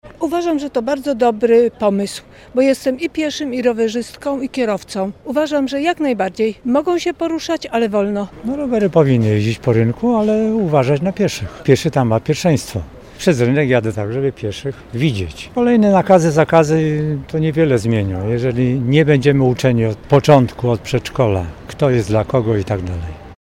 03_sonda-rowery.mp3